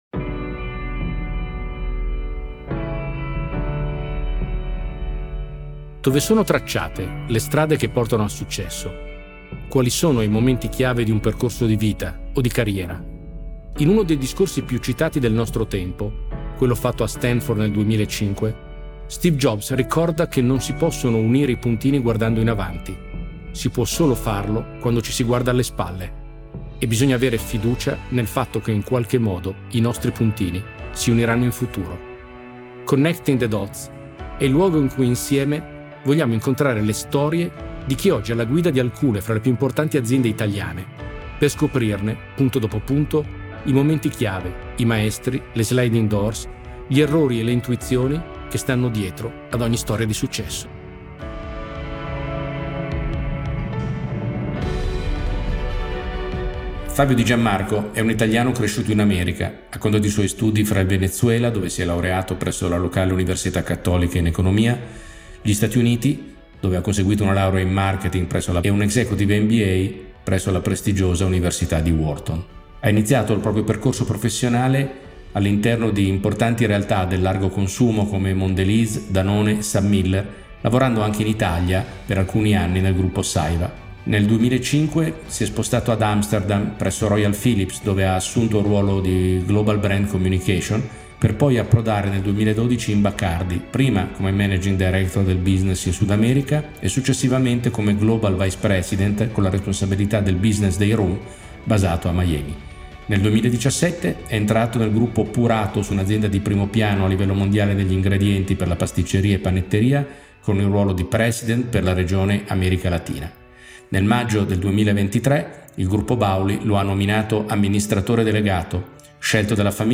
Chaberton Partners is proud to present Connecting the Dots - The invisible path to success -, an exclusive series of CEOs interviews.